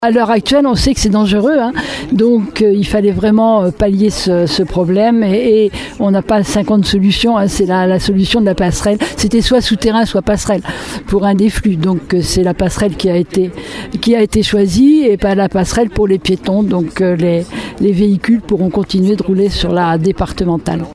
Une route qui est traversée chaque jour par plus de 7 700 véhicules et qui pose aujourd’hui de gros problèmes de sécurité, comme le souligne Catherine Desprez, maire de Surgères :